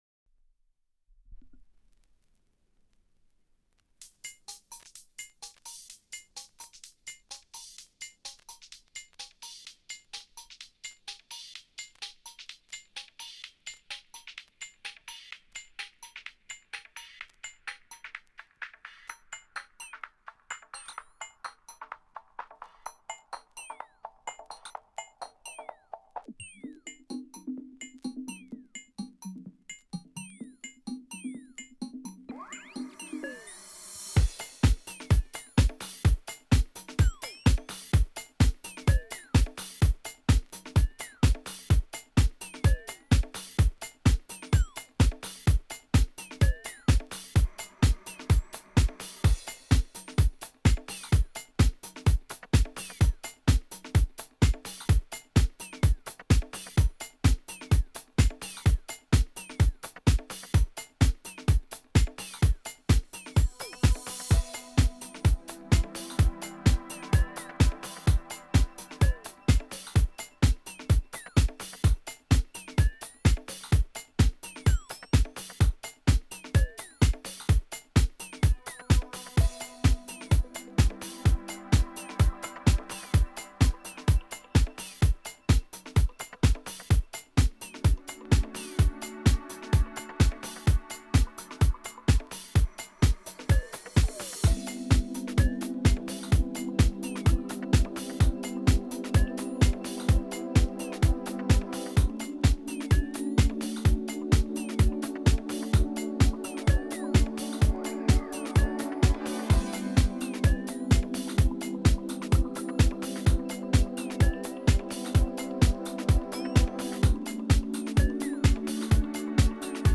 It’s from 2002 and its mostly vocal house.